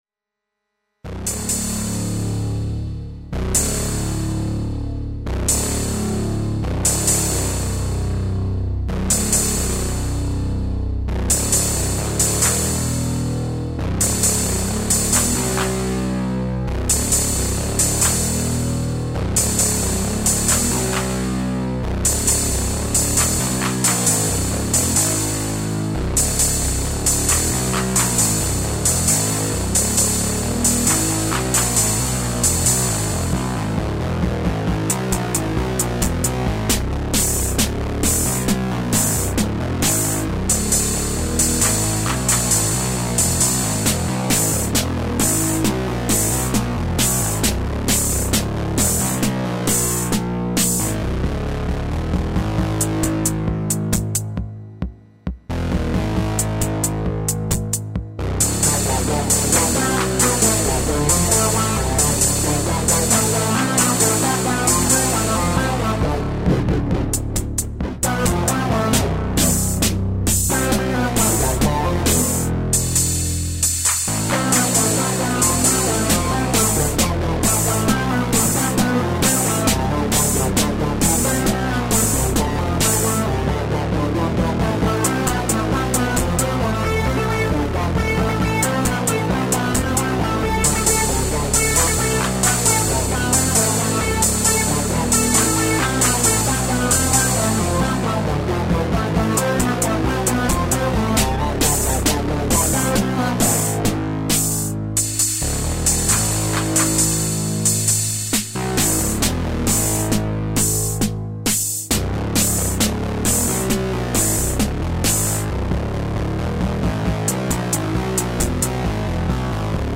Kopfhörer oder Stereo-Lautsprecher empfohlen.
Moog Subsequent 25, RD-6
Reverb Room
Bassline mit dem Moog Subsequent